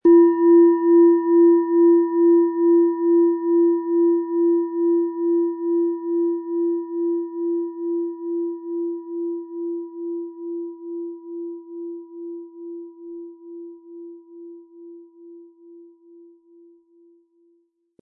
Tibetische Universal-Klangschale, Ø 12,9 cm, 320-400 Gramm, mit Klöppel
Der kräftige Klang und die außergewöhnliche Klangschwingung der traditionellen Herstellung würden uns jedoch fehlen.
Den passenden Schlägel erhalten Sie kostenlos mitgeliefert, er lässt die Klangschale harmonisch und wohltuend ertönen.